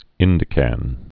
(ĭndĭ-kăn)